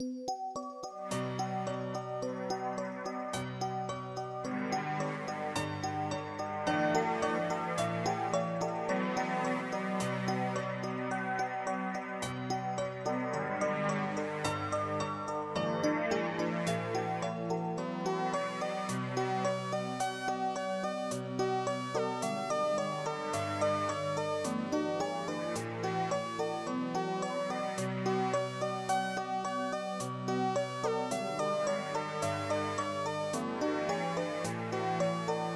A small Spacy - mistery theme